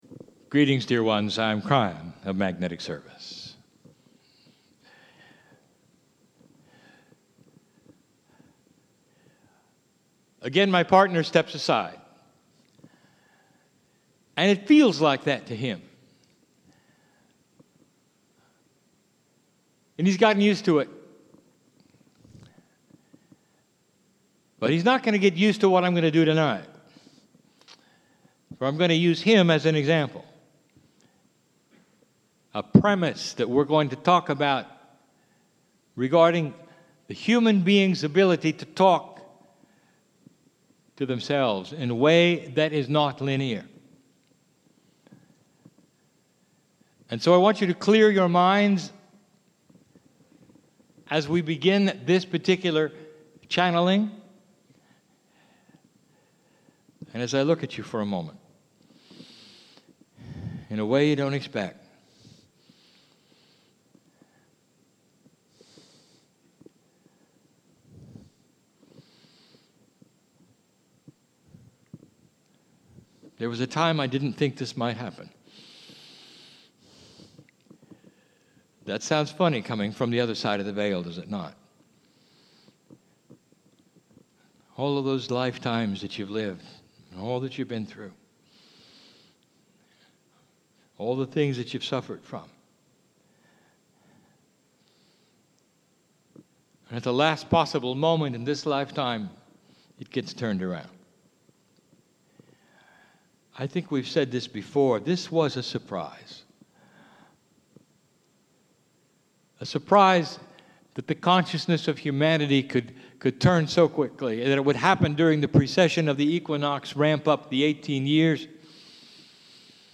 2013 "Opening the Door" Live Channelling
KRYON CHANNELLING "OPENING THE DOOR" 37:20 minutes 44.8 megabytes Boston 9-13.mp3 This MP3 file of a Kryon channelling is free to you.